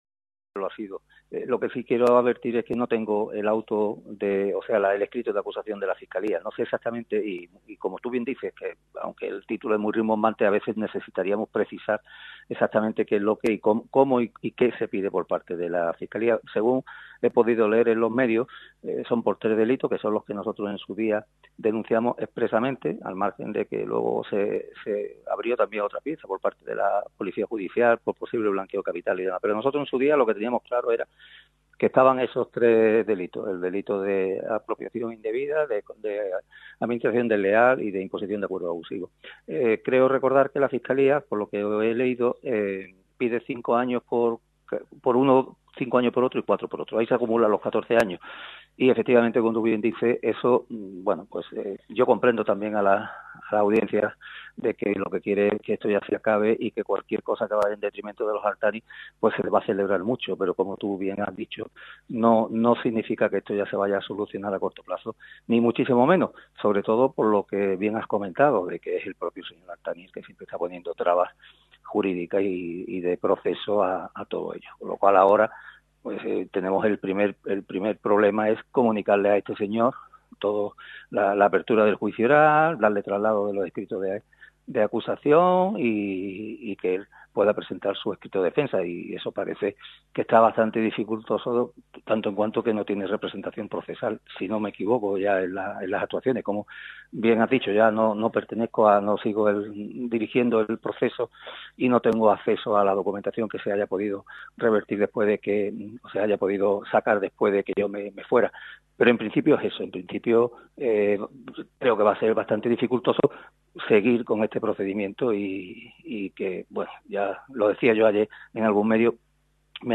atendió a Radio MARCA Málaga en una entrevista en la que habla con claridad del futuro del caso Al-Thani.